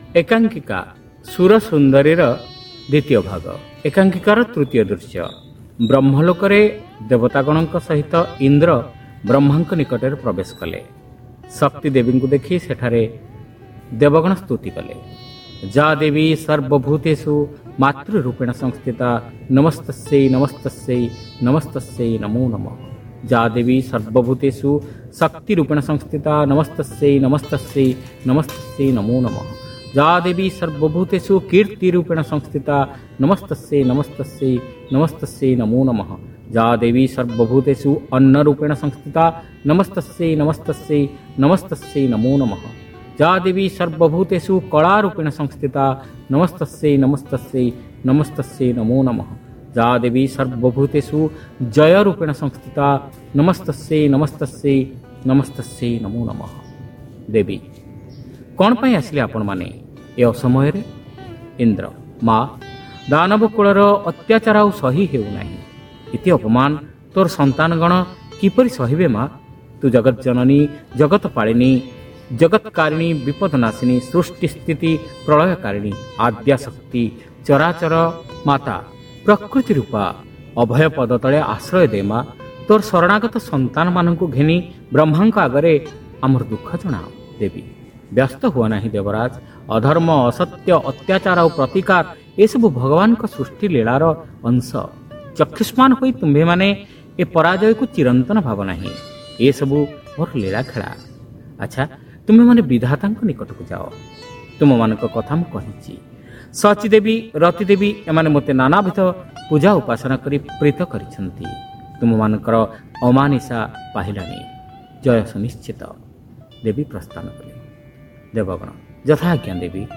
Audio One act Play : Sura Sundari (Part-2)